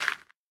minecraft / sounds / dig / gravel3.ogg
gravel3.ogg